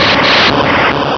Cri_0101_DP.ogg